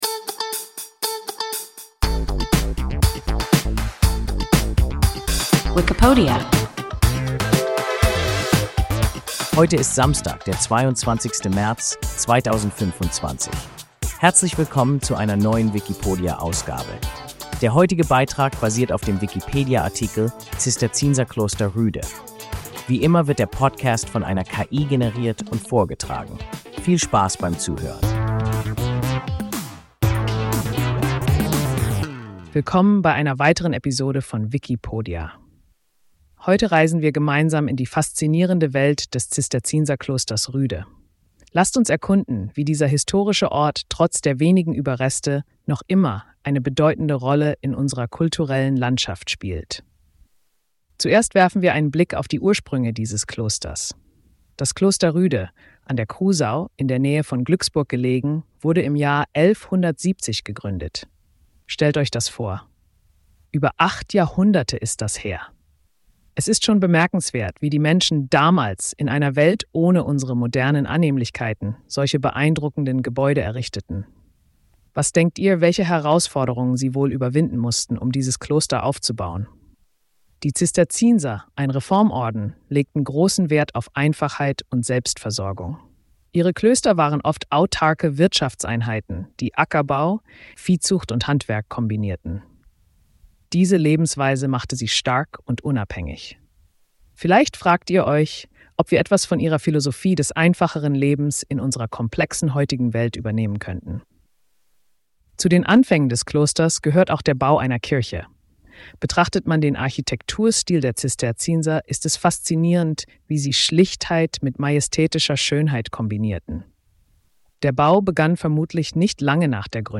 Zisterzienserkloster Rüde – WIKIPODIA – ein KI Podcast